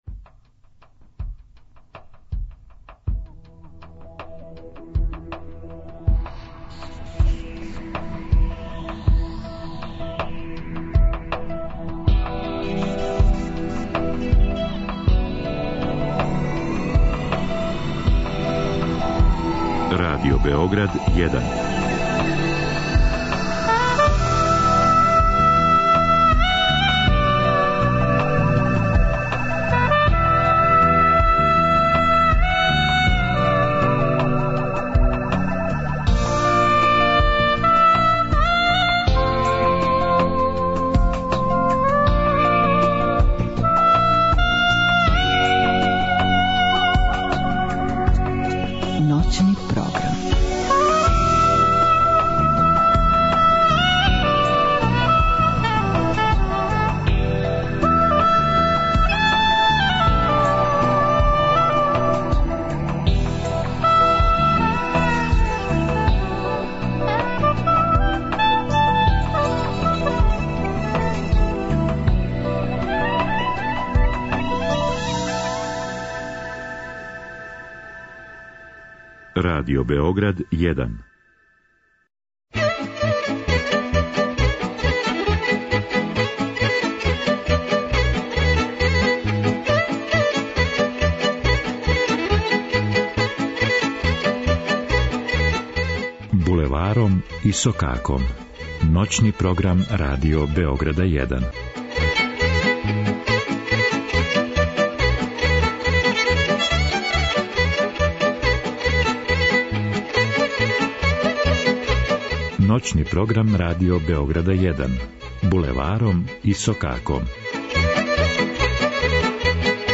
Као и сваког понедељка на уторак, слушаоци ће бити у прилици да уживају у провереним вредностима наше традиционалне музике. Поред редовних рубрика, биће приче о настанку и ауторима појединих песама, које већ дуже време живе као народне.